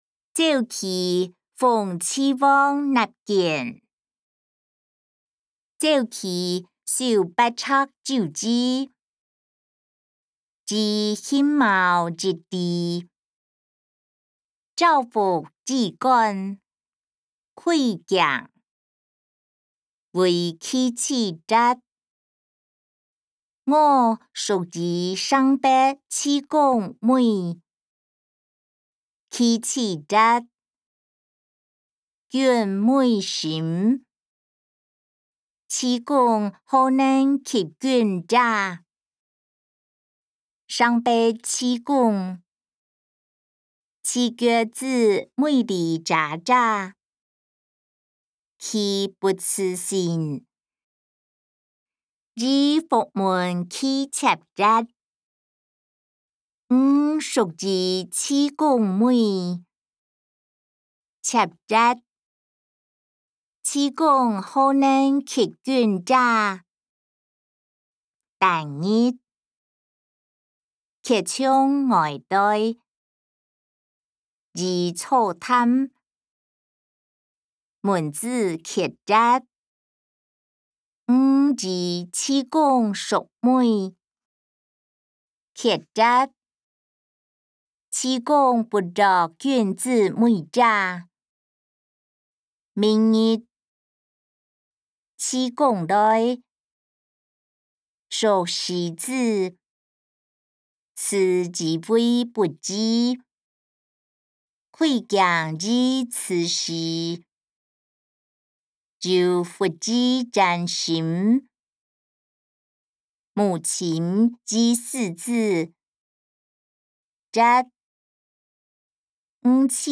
歷代散文-鄒忌諷齊王納諫音檔(海陸腔)